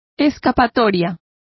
Complete with pronunciation of the translation of loopholes.